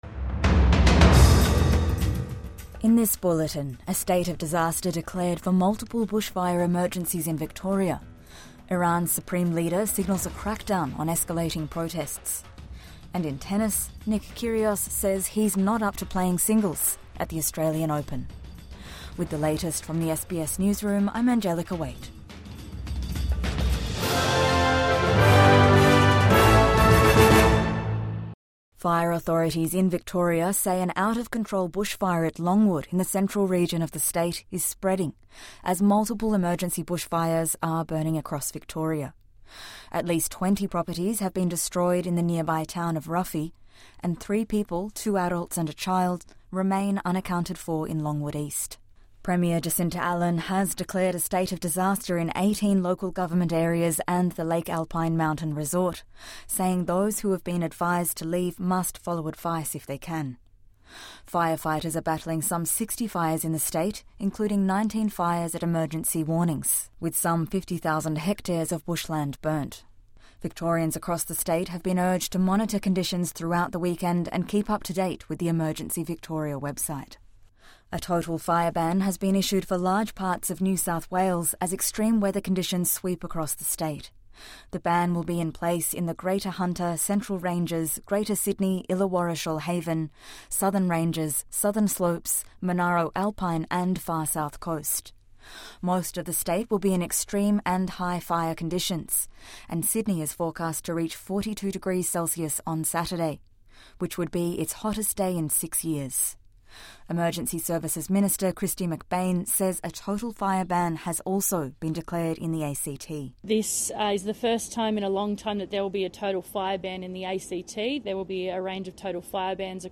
State of disaster declared for bushfire emergencies in Victoria | Morning News Bulletin 10 January 2026